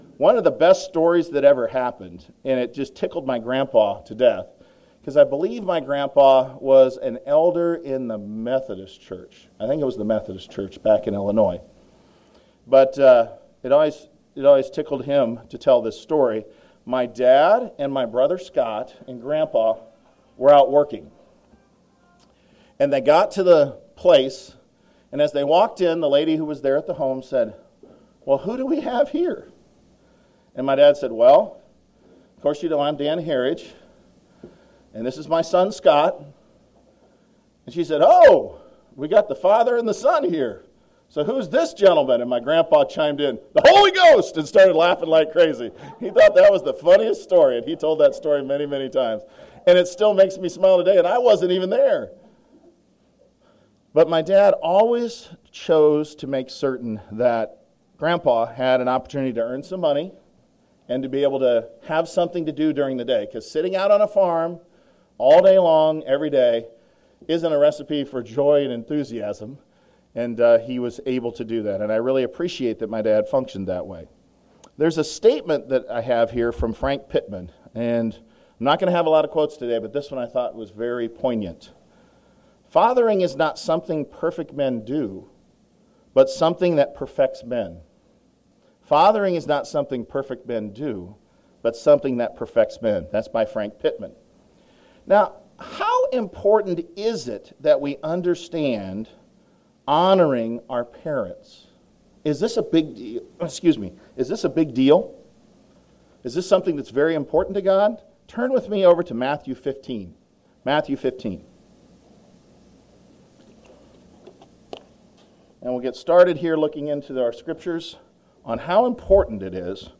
Sermons
Given in Omaha, NE